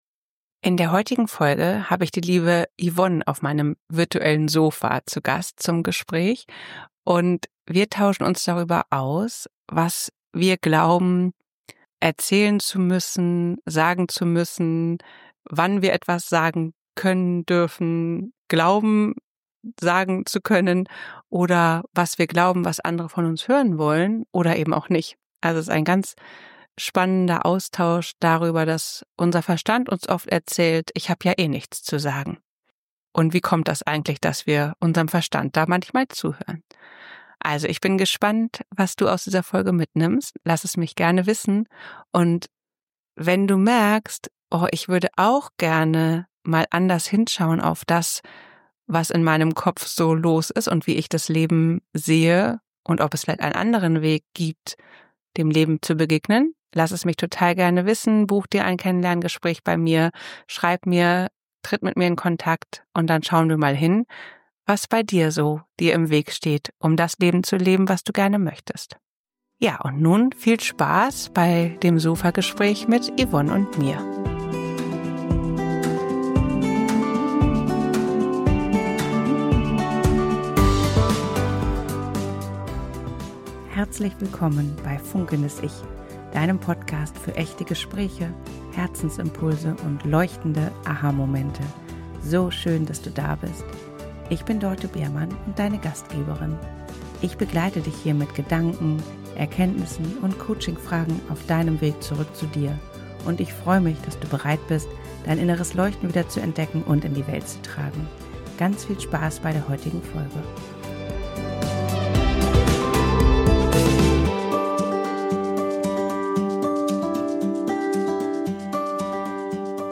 Hör rein in dieses ehrliche Gespräch – vielleicht erkennst du dich wieder.